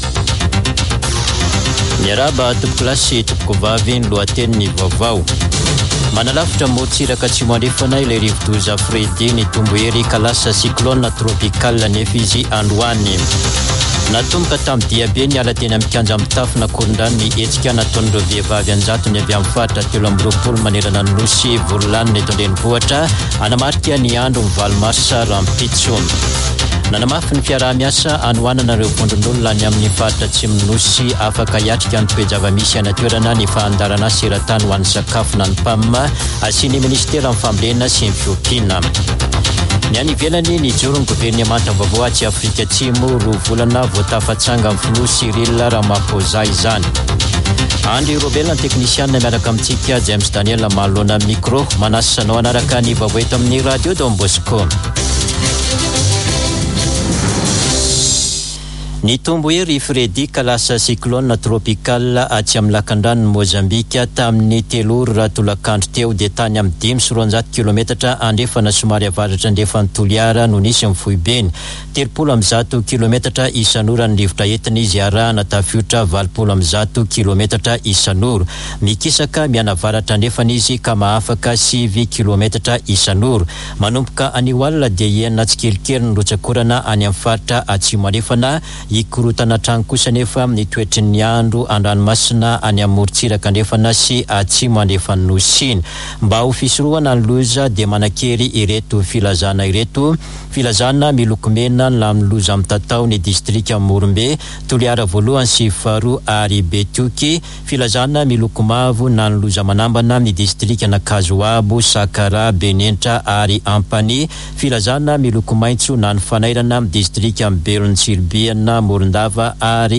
[Vaovao hariva] Talata 7 marsa 2023